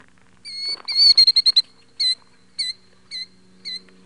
killdeer.wav